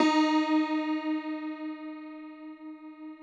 53r-pno11-D2.wav